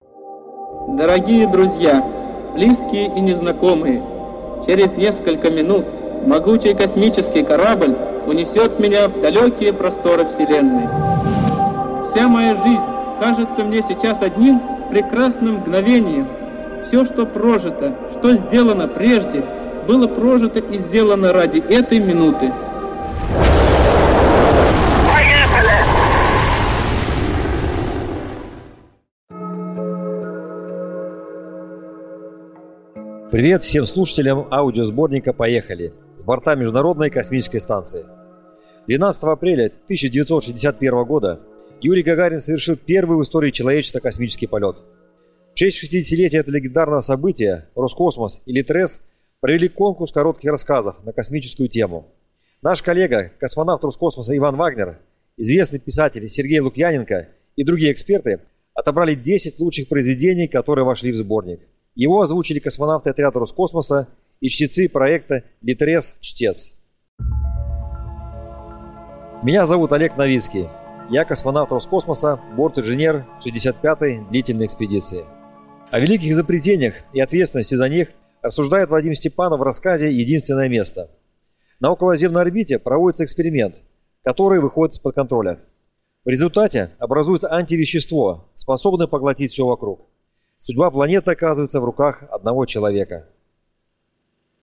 Aудиокнига Поехали!